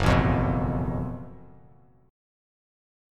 F#mM7bb5 chord